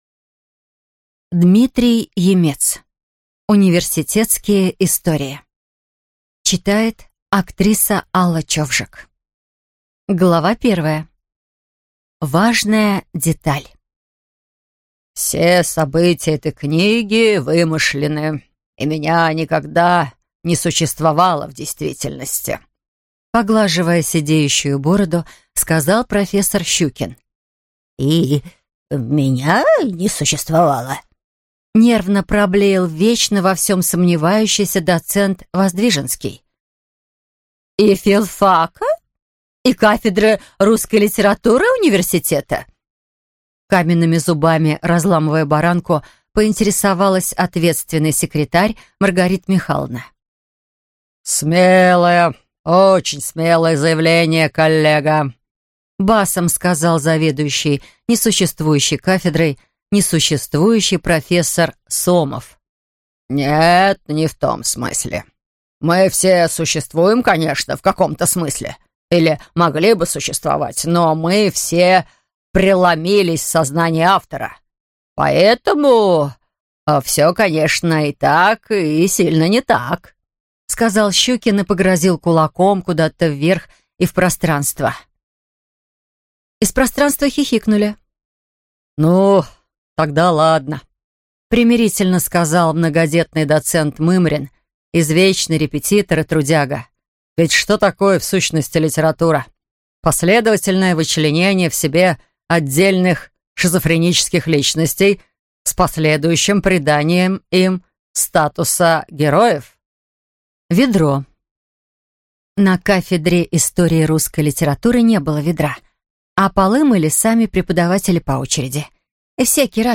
Aудиокнига Университетские истории